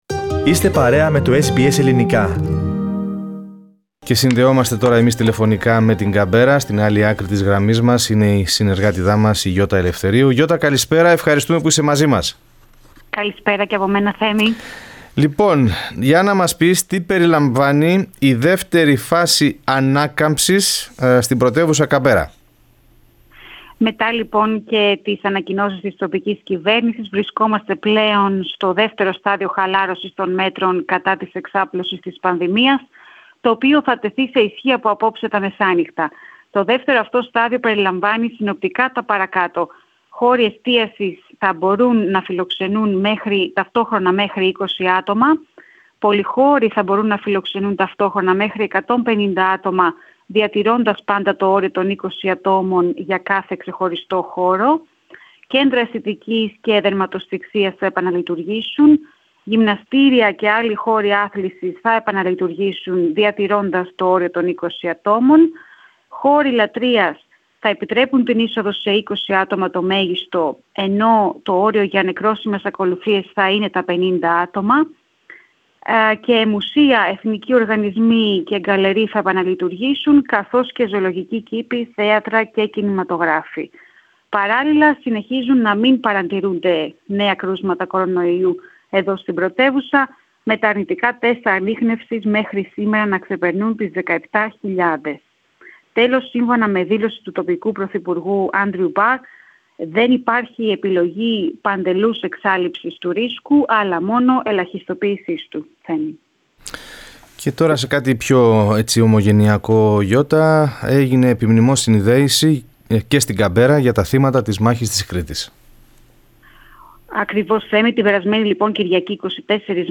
Ακούστε περισσότερα στην ανταπόκριση